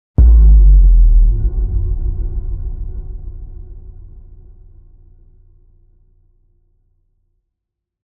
Download Boom sound effect for free.
Boom